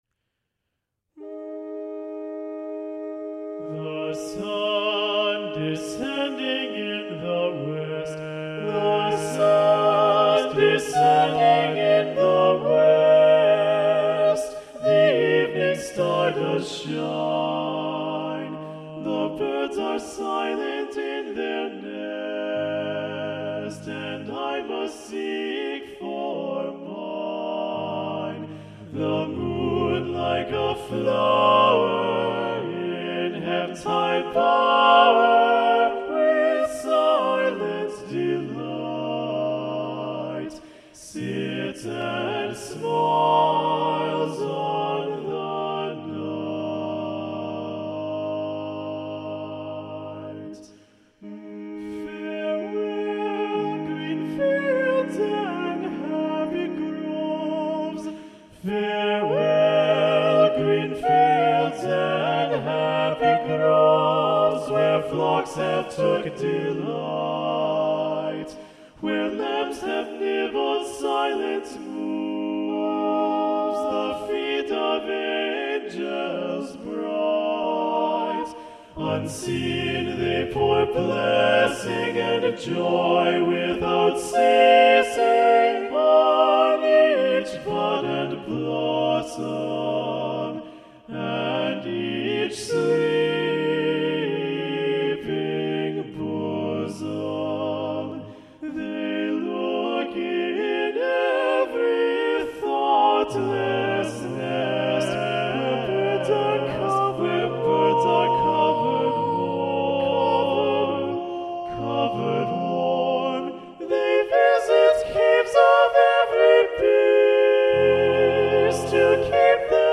Choral ~ General ~ A Cappella
A tranquil setting